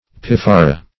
Search Result for " piffara" : The Collaborative International Dictionary of English v.0.48: Piffero \Pif"fe*ro\, Piffara \Pif"fa*ra\, n. [It. piffero.]